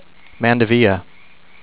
man-de-VEE-uh